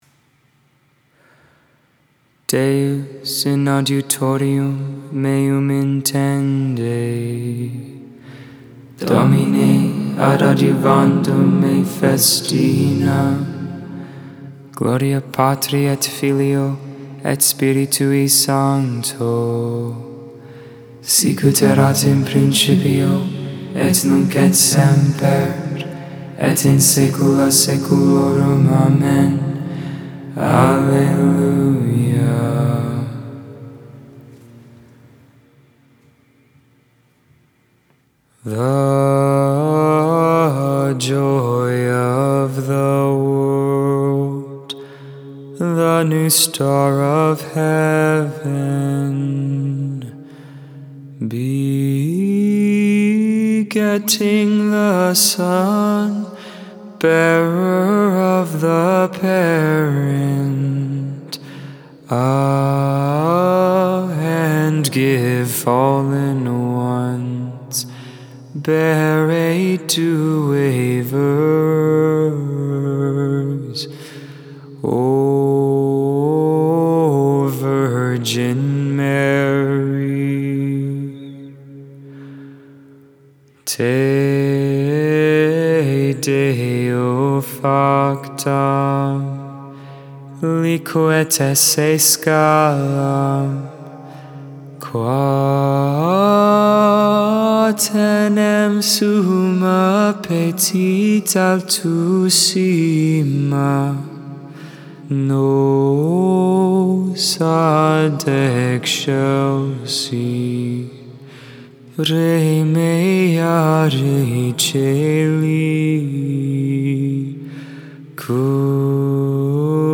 8.15.21 Vespers, Sunday Evening Prayer